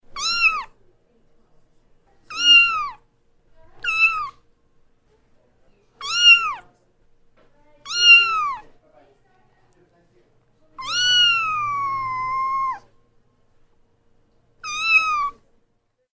Котенок мяукает